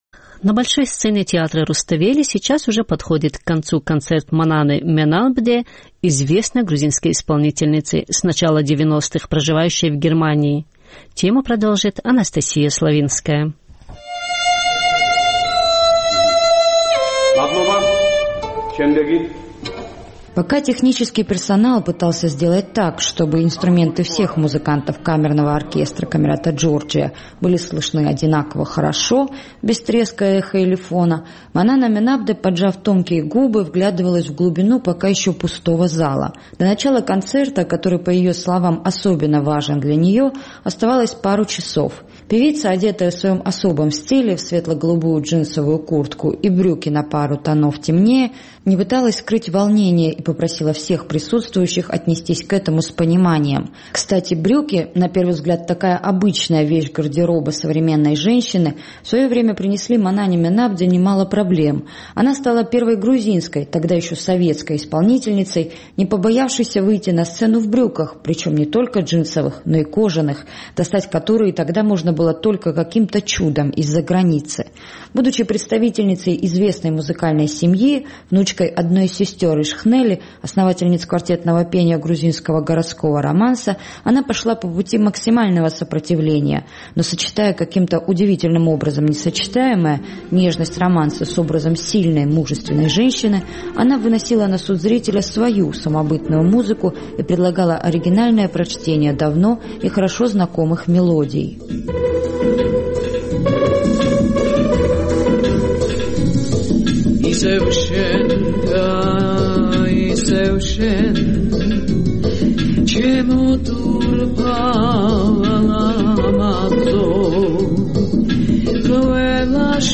На большой сцене театра Руставели в эти минуты подходит к концу концерт Мананы Менабде – известной грузинской исполнительницы, с начала 90-х проживающей в Германии.